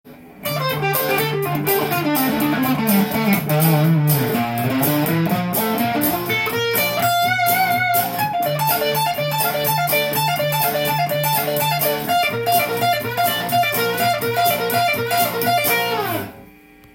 Dm/Dm/B♭/B♭/
Dmペンタトニックスケール
dm.penta_.m4a